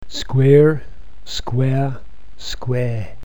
However, a newer, monophthongal pronunciation is becoming common in Britain, and is regular in Australia, New Zealand and South Africa: instead of and you will hear legnthened versions of DRESS and KIT: and .
Rhotic RP, South of England Newer Non-rhotic
SQUARE